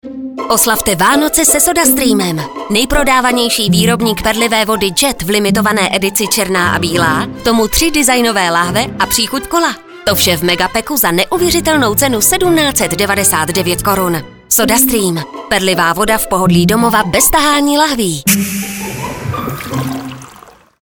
Do konce Vánoc budou na stanicích Evropa 2, Blaník, Frekvence 1 a Rádio Kiss vysílány dva dvacetisekundové spoty v rovnoměrné rotaci.